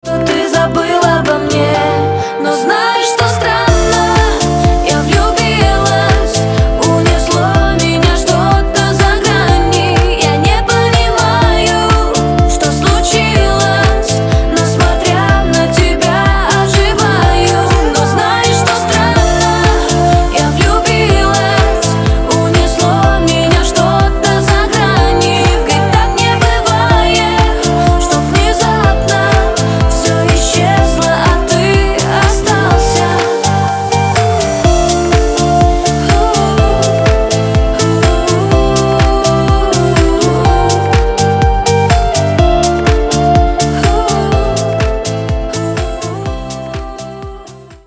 • Качество: 256, Stereo
красивые
женский вокал
dance